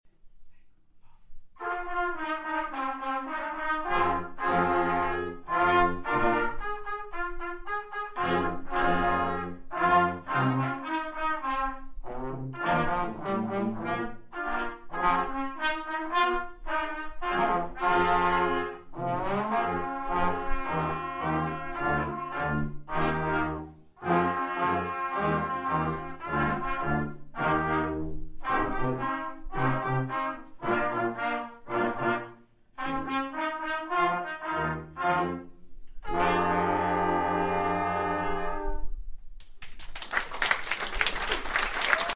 Junior Brass